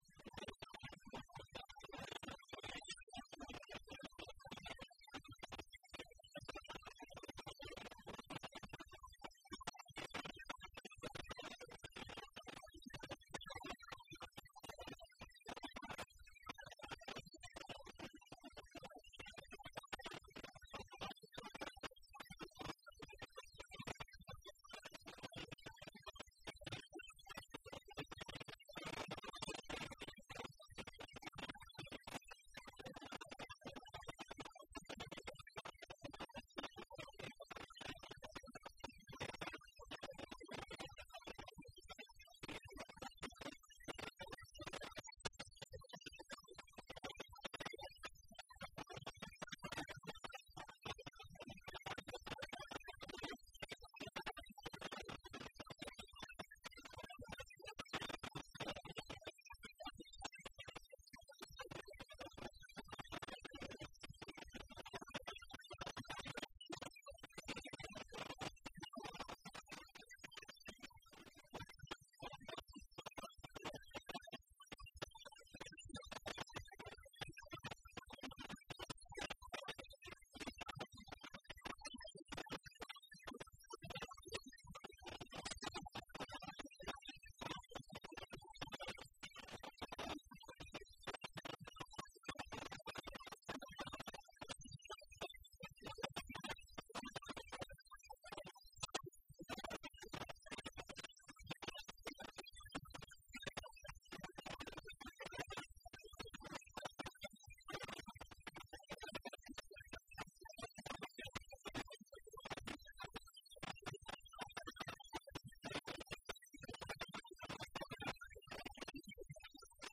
تهجد ليلة 25 رمضان 1432هـ من سورتي النساء (148-176) و المائدة (1-50) Tahajjud 25 st night Ramadan 1432H from Surah An-Nisaa and AlMa'idah > تراويح الحرم النبوي عام 1432 🕌 > التراويح - تلاوات الحرمين